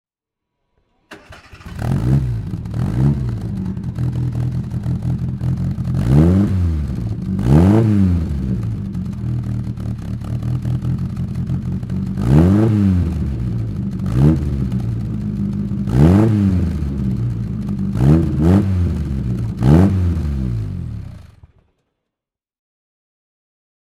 Austin-Healey Sprite (1959) - Starten und Leerlauf
Austin-Healey_Sprite_1959.mp3